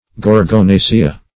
(g[^o]r`g[-o]*n[=a]"sh[-e]*[.a])